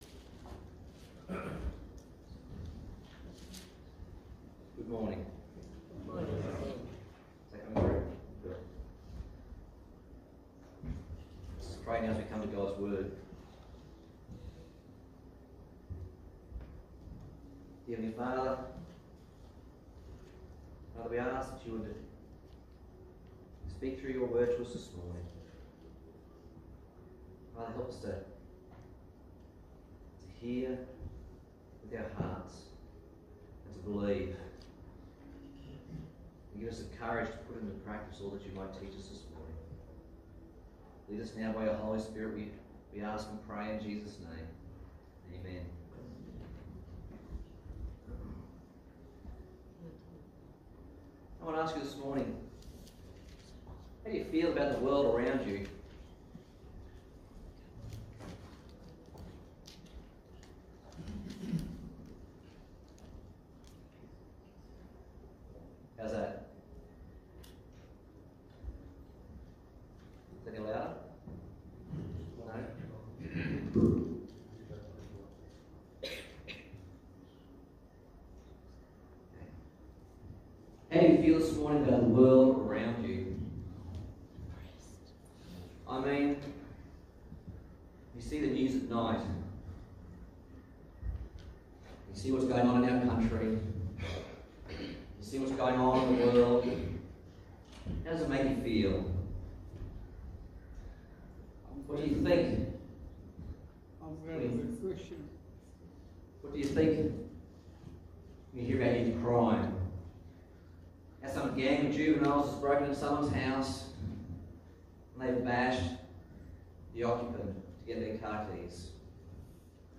Passage: Genesis 18:16-33 Service Type: Sunday Morning